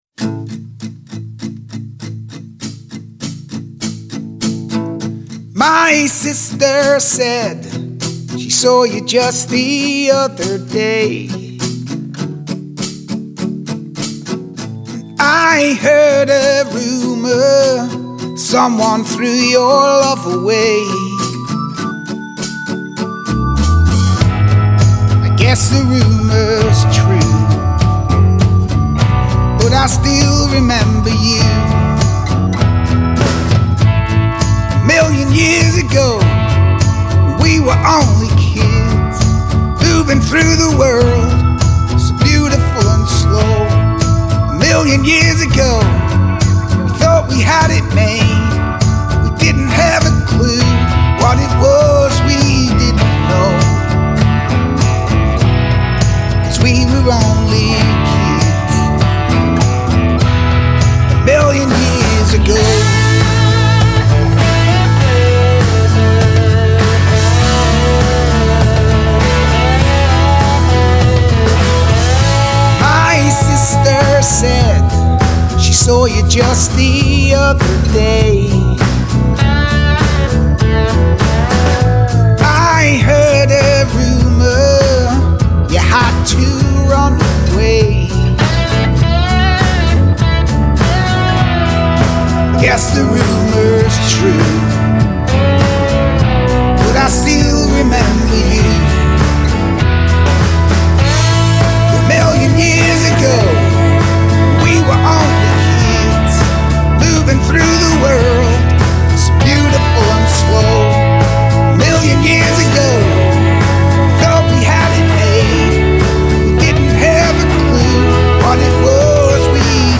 slickly produced, pop-leaning rock track